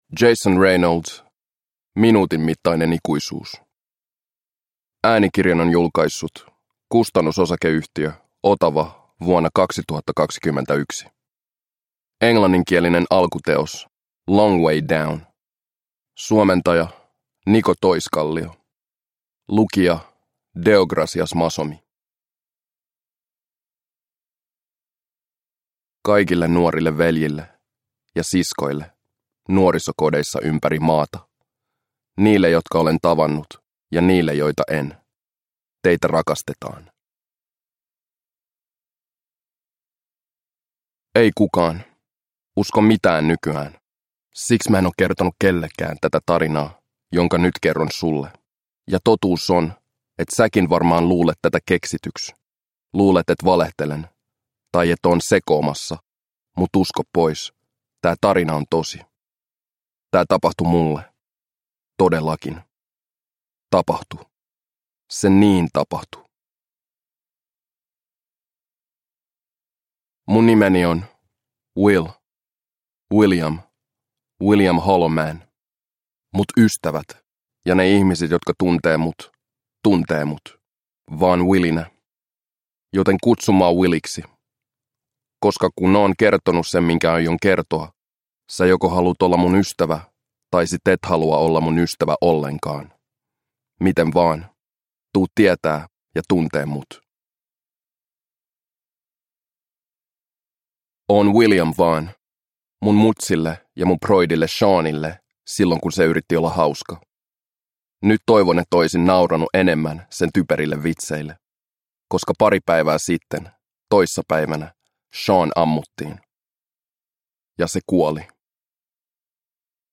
Minuutin mittainen ikuisuus – Ljudbok – Laddas ner